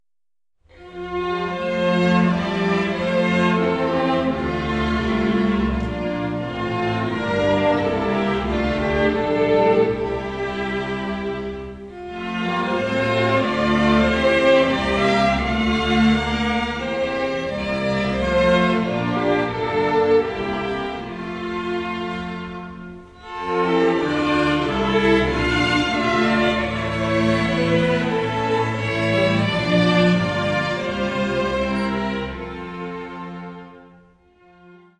Streichensemble